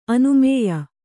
♪ anumēya